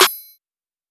Clap Wake Up.wav